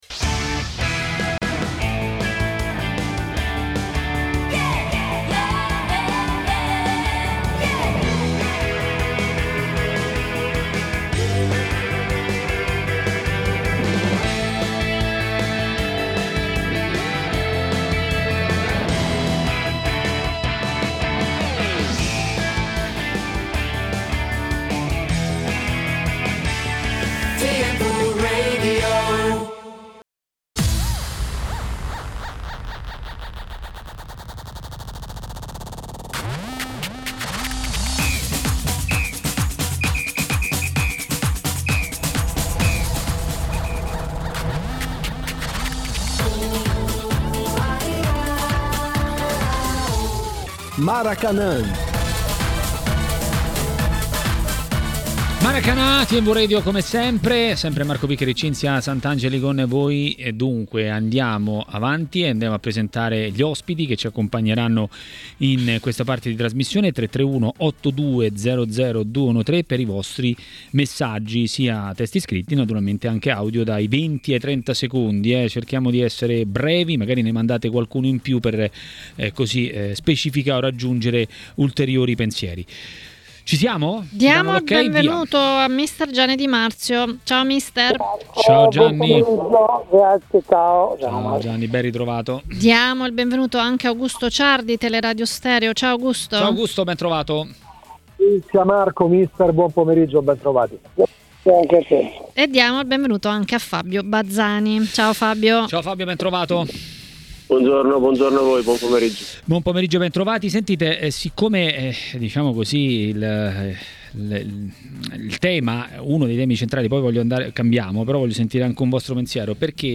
L'ex attaccante Fabio Bazzani a Maracanà, trasmissione di TMW Radio, ha detto la sua sui temi del momento.